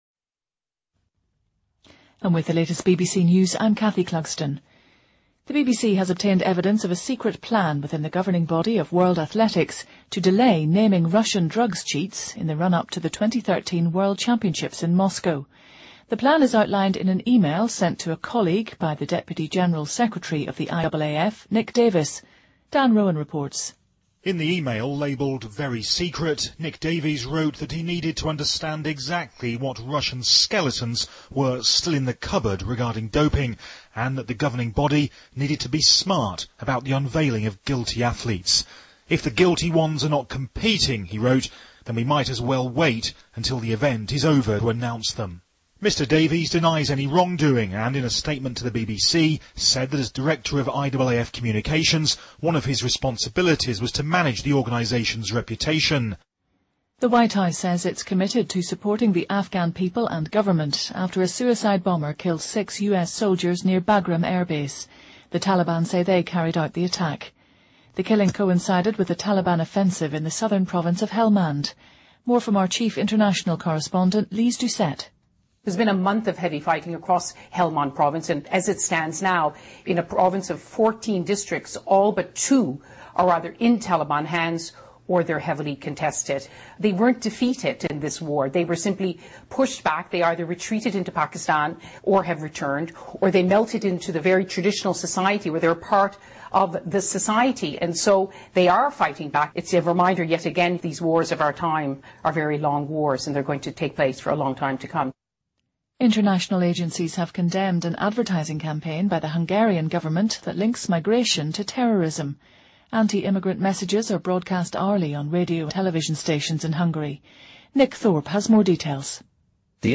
日期:2015-12-23来源:BBC新闻听力 编辑:给力英语BBC频道